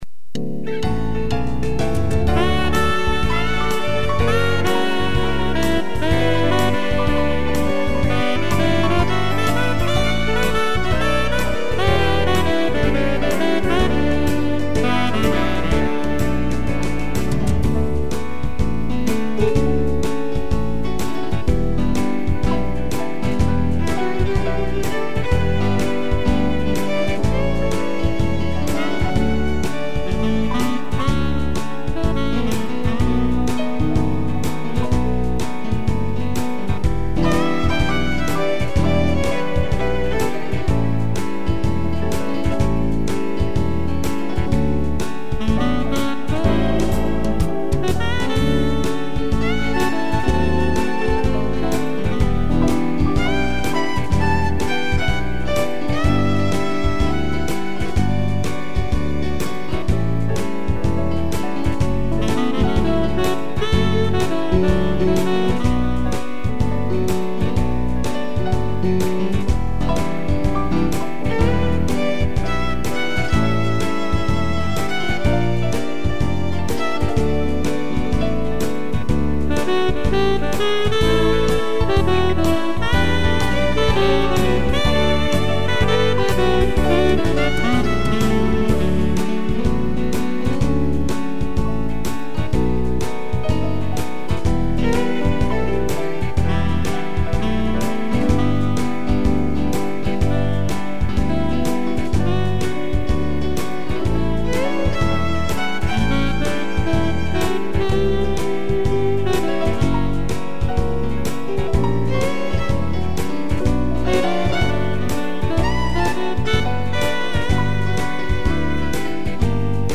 piano, sax e violino
(instrumental)